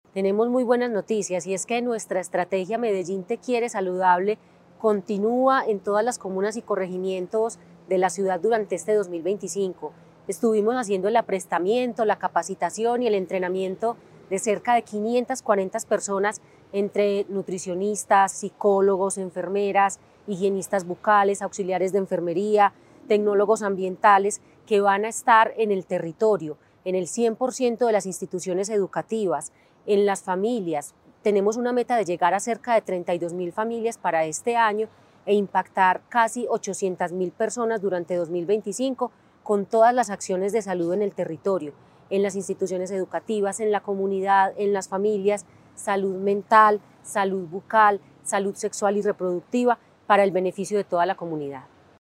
Palabras de Natalia López, secretaria de Salud